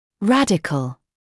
[‘rædɪkl][‘рэдикл]радикальный; радикал